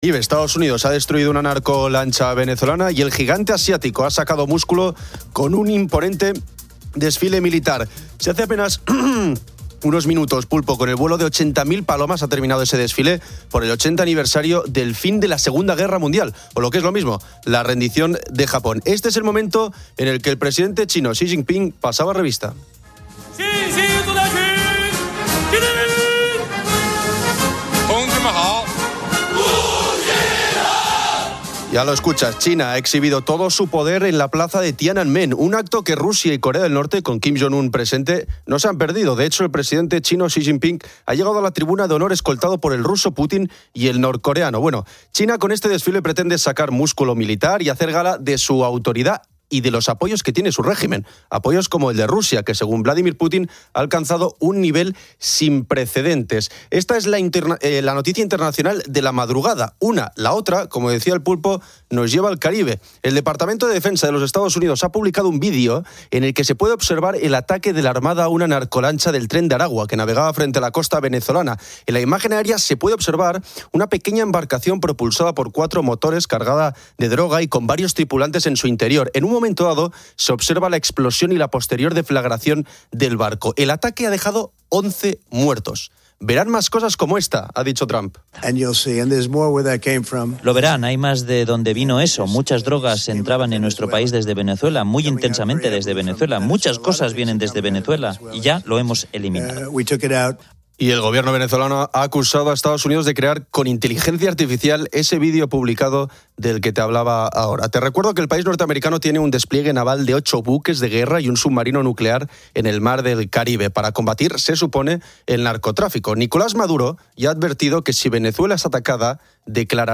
Los oyentes del programa "Poniendo las Calles" comparten sus experiencias al volver a la rutina, sus diversos trabajos (camioneros, mineros, cocineros, electricistas) y cómo el programa los acompaña. También se aborda la polémica práctica del "icing" (aparcar coches de combustión en plazas de eléctricos) y las diferencias en el coste de aparcar vehículos eléctricos en ciudades españolas.